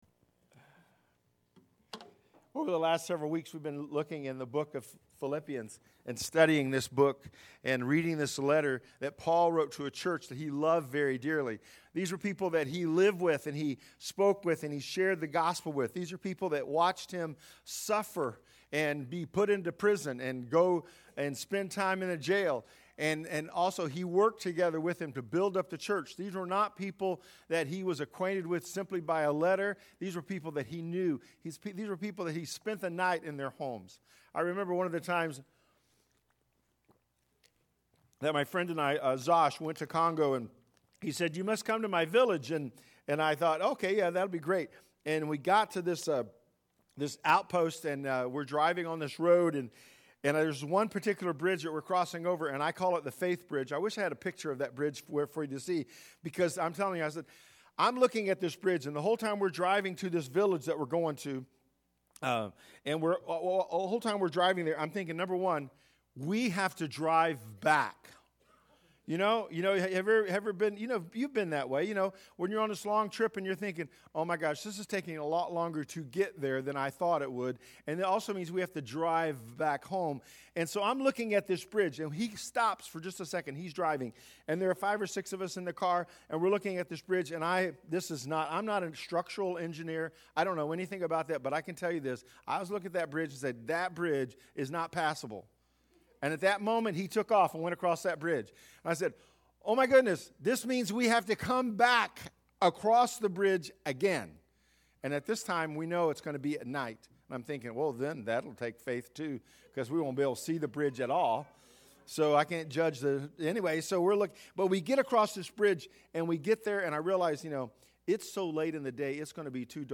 I Choose Joy-Preaching Through Philippians